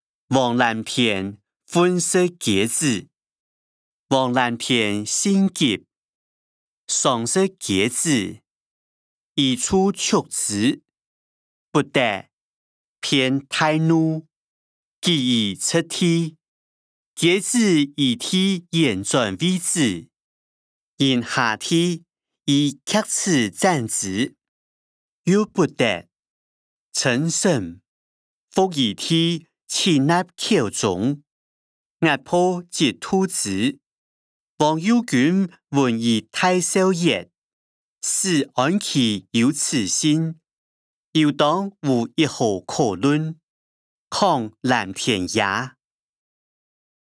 小說-王藍田忿食雞子音檔(四縣腔)